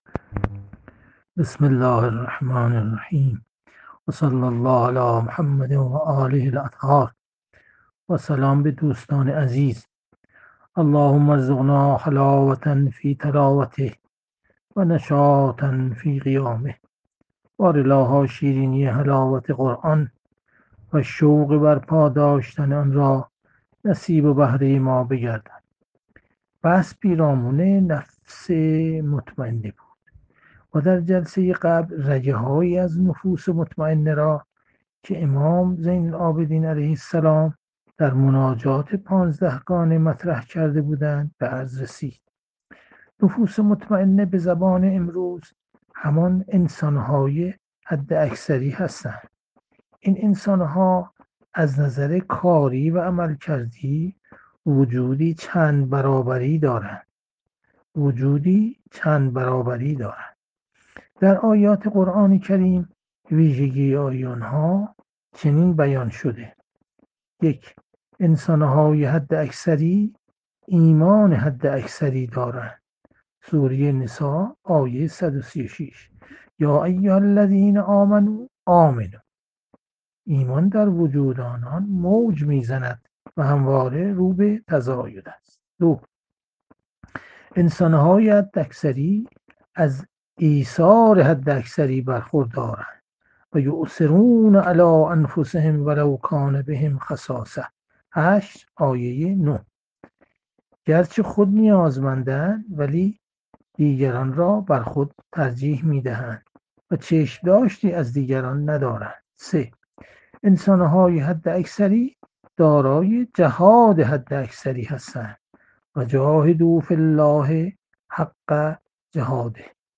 جلسه مجازی هفتگی قرآنی، سوره فجر، 15 خرداد 1401